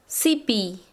Dialect: nor oriental de Tayacaja-Huancavelica